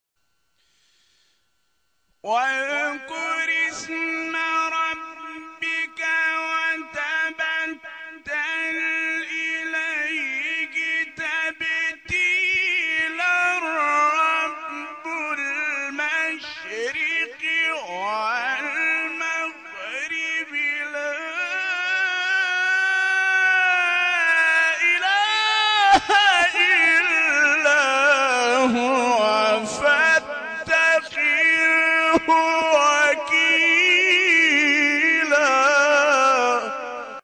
گروه شبکه اجتماعی: فرازهای صوتی از تلاوت قاریان بین‌المللی و ممتاز کشور را که به تازگی در شبکه‌های اجتماعی منتشر شده است، می‌شنوید.